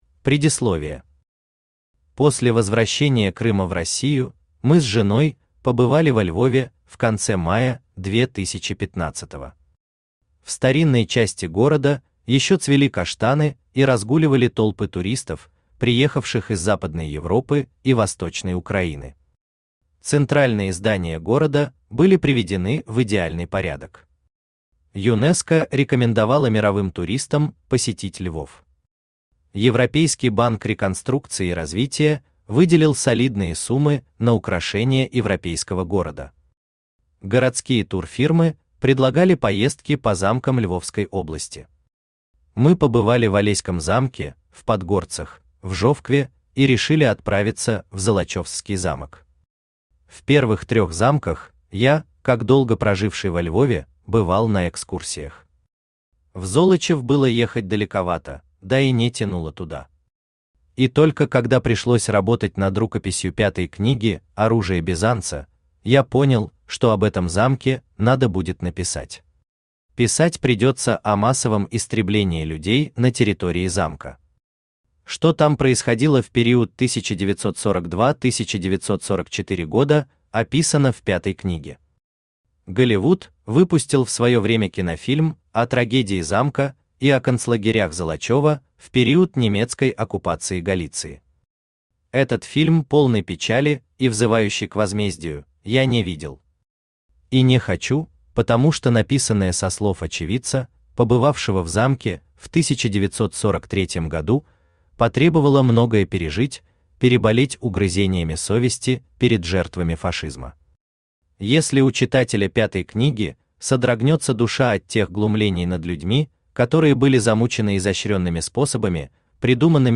Aудиокнига Большой крест Автор Вадим Гринёв Читает аудиокнигу Авточтец ЛитРес. Прослушать и бесплатно скачать фрагмент аудиокниги